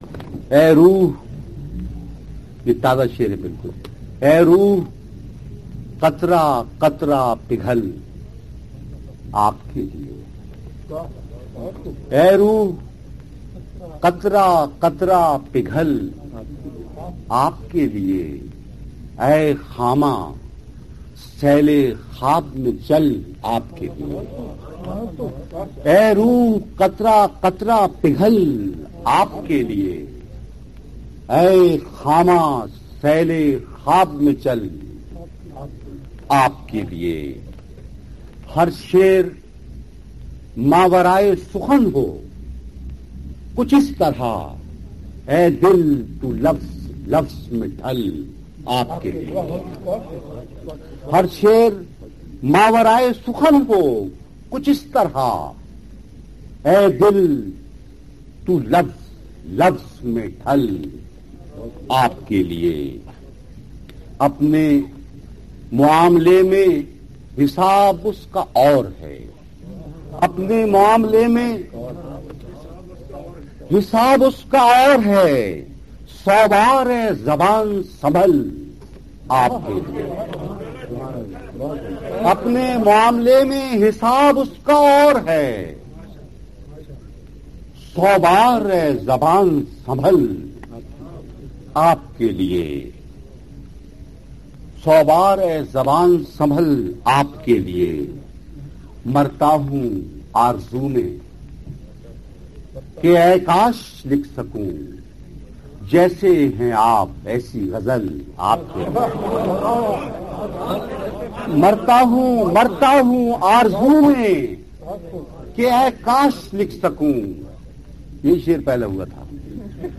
لائبریری منظوم کلام نظمیں (Urdu Poems) عبیداللہ علیم پلے لسٹ Playlist اے روح قطرہ قطرہ پگھل آپ کے لئے Ay rooh qatra qatra کلام عبیداللہ علیم Poem by Obaidullah Aleem آواز: عبیداللہ علیم Voice: Obaidullah Aleem mp3